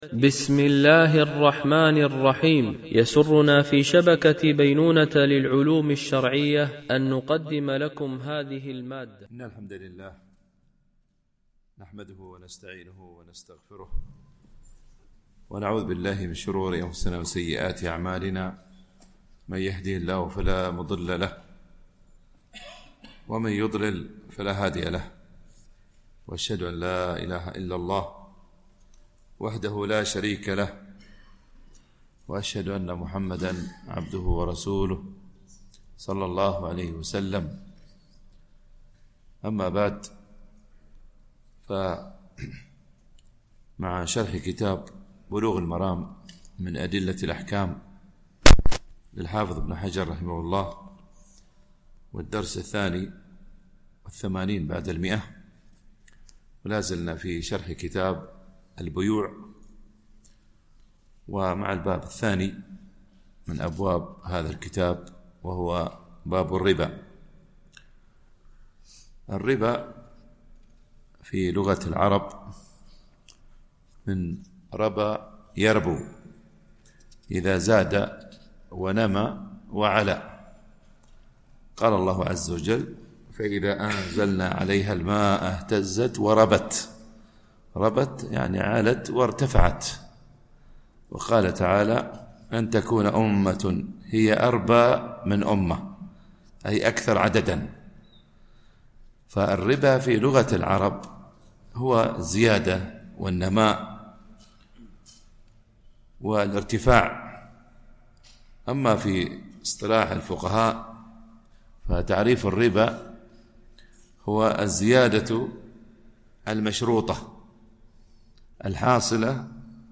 MP3 Mono 44kHz 64Kbps (CBR)